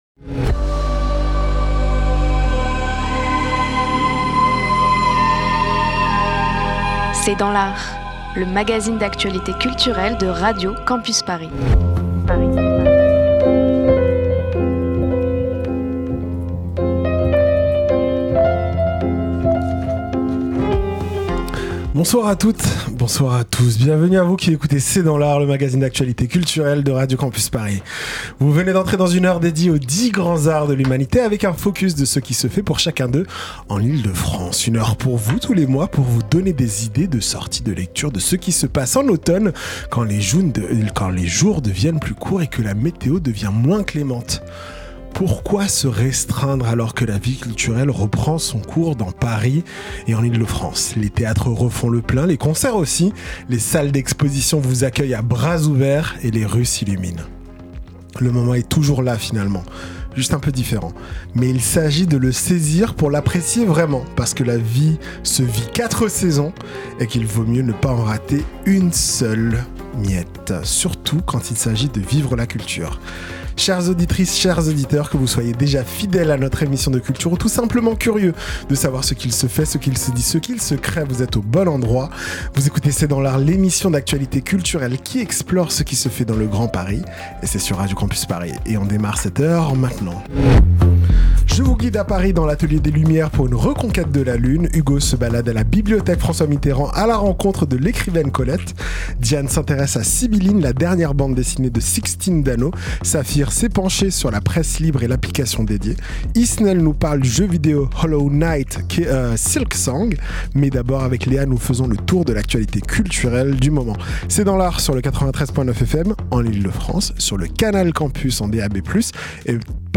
C’est dans l’art, c’est l’émission d'actualité culturelle de Radio Campus Paris.